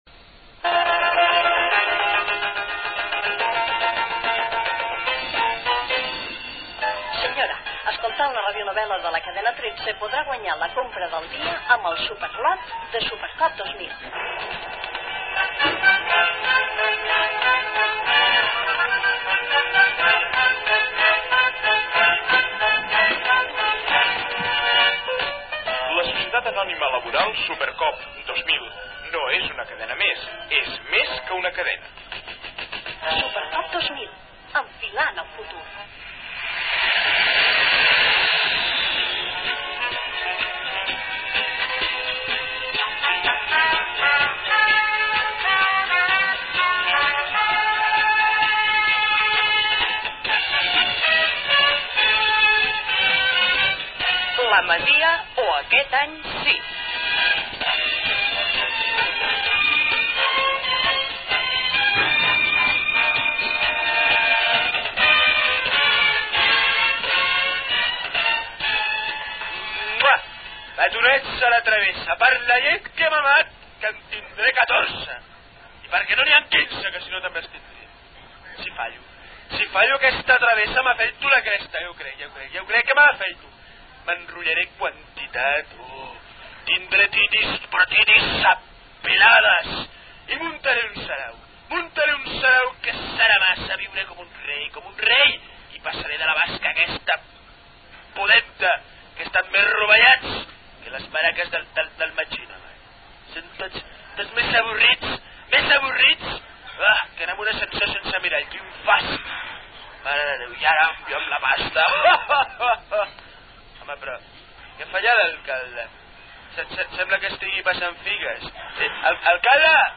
Radionovel·la «La masia o aquest any, sí!»
Careta del programa (veu Pepa Fernández), monòleg de Colau "El punki" i diàleg d'aquest amb l'alcalde.
Ficció